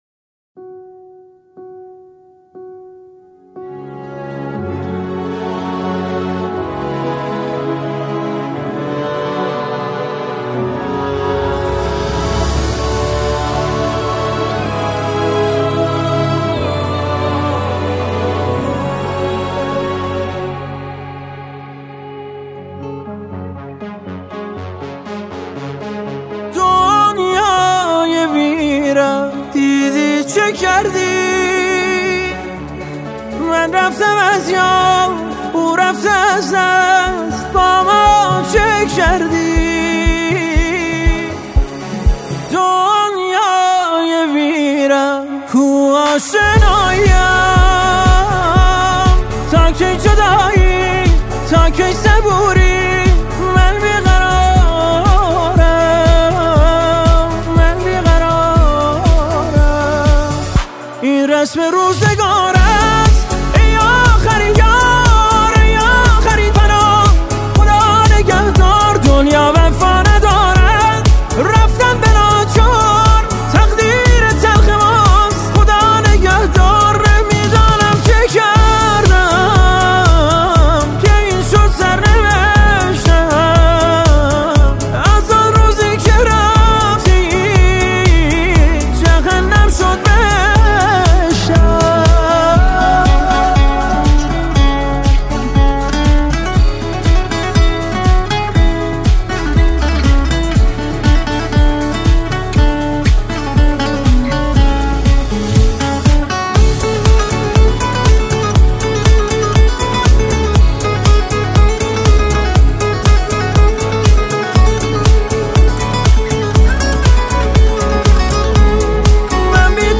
اهنگ احساسی و زیبای